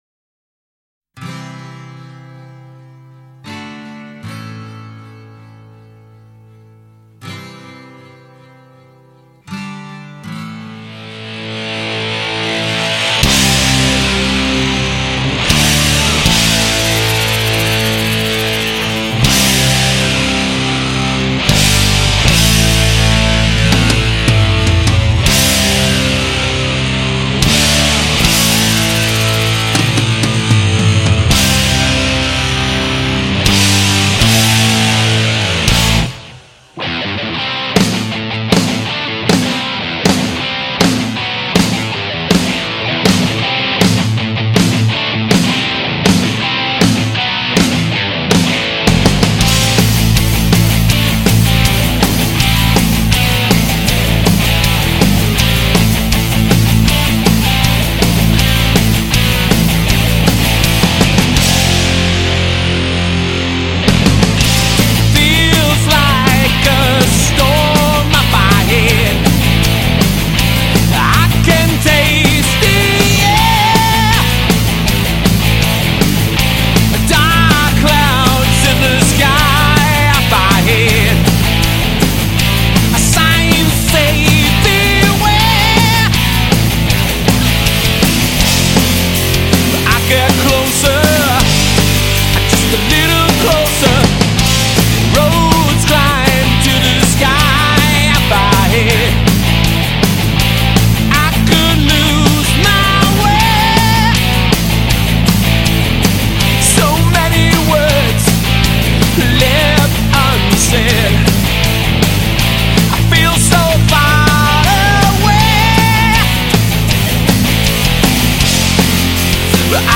North East rockers
original hard rock band